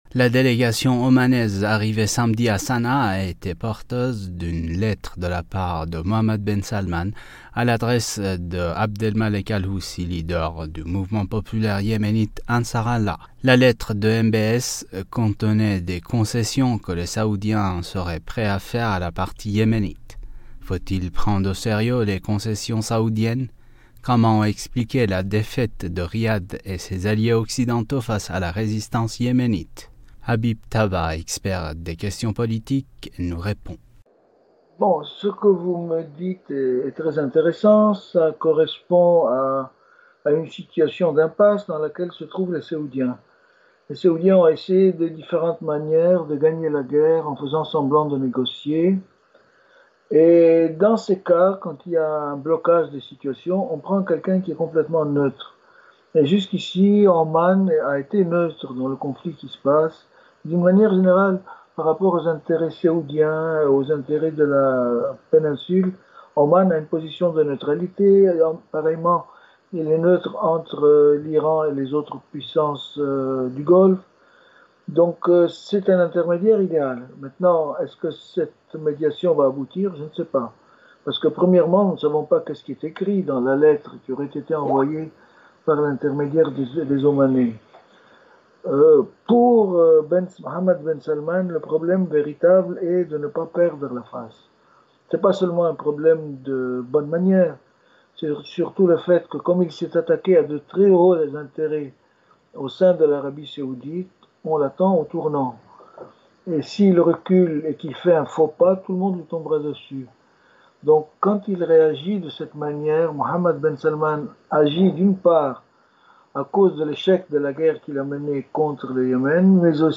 » Mots clés Yémen usa interview Eléments connexes L’Europe fera-t-elle appel à l’Iran et au Yémen pour protéger le Groenland face aux États-Unis ?